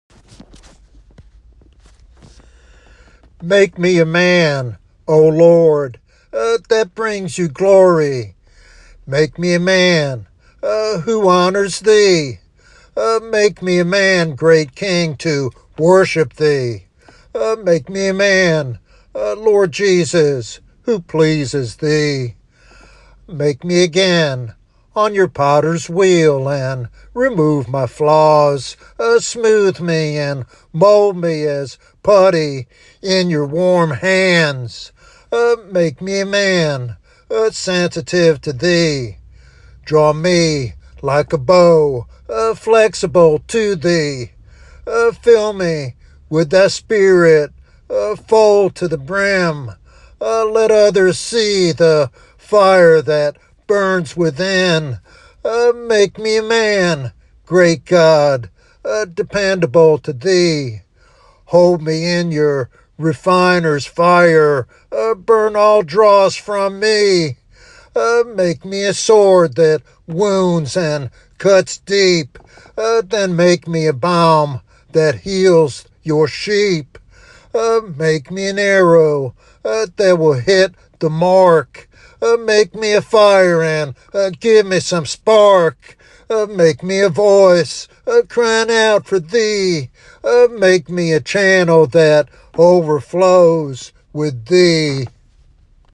The speaker prays for God to transform him into a man who glorifies, honors, and serves Him fully.